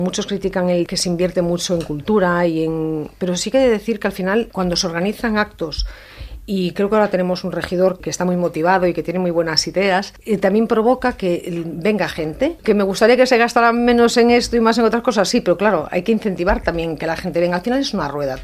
“No posarem pals a les rodes si hi ha propostes interessants per a Calella”, ha dit a l’entrevista política de Ràdio Calella TV d’aquesta setmana, quan ha recordat la predisposició de l’executiu de Marc Buch d’incorporar iniciatives de l’oposició.